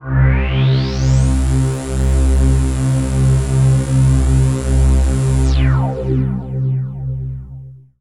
RAVEPAD 01-LR.wav